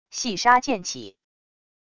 细沙溅起wav音频